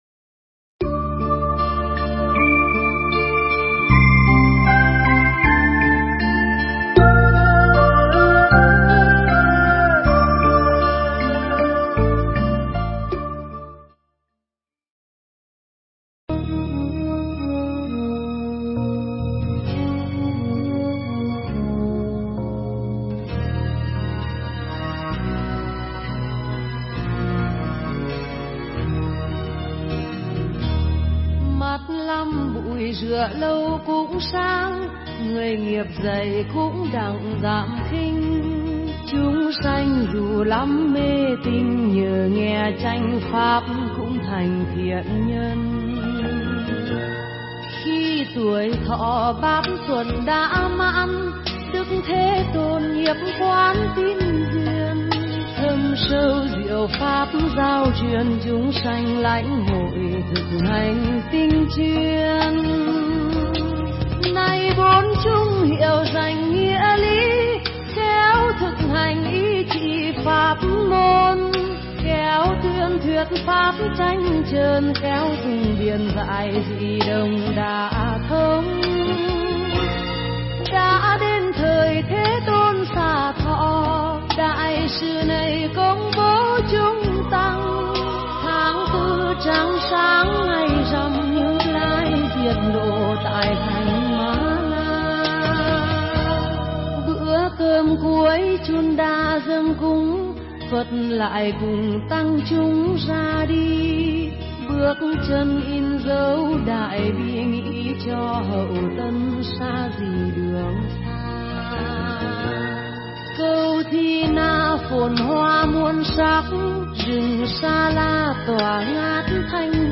Nghe Mp3 thuyết pháp Người Biết Sống Một Mình